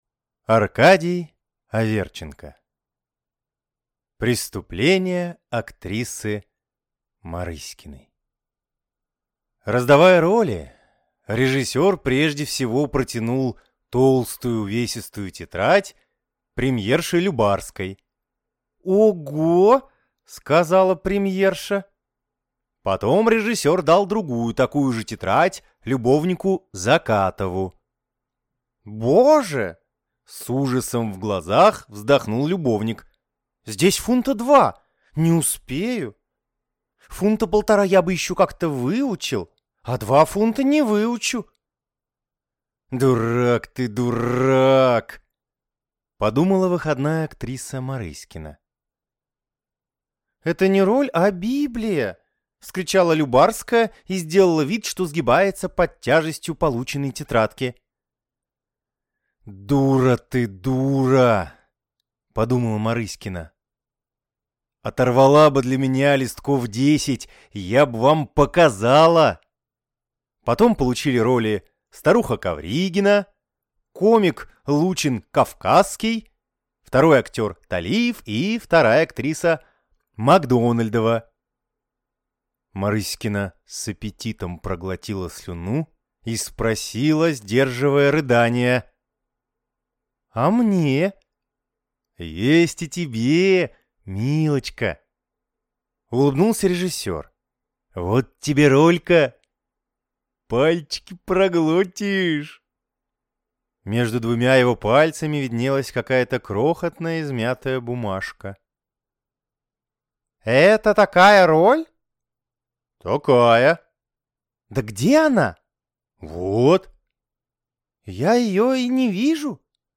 Аудиокнига Преступление актрисы Марыськиной | Библиотека аудиокниг